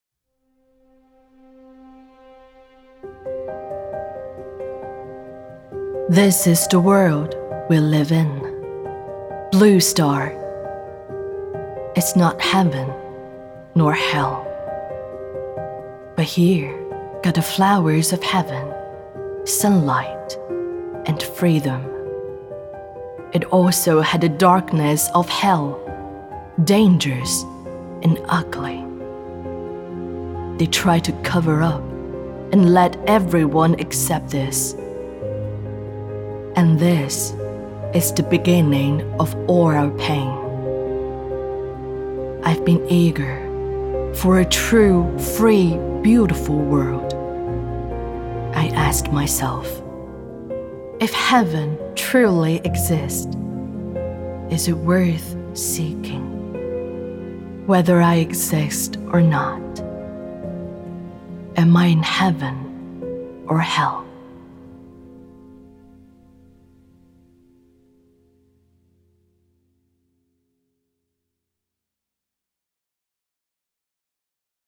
品牌广告-亲和魅力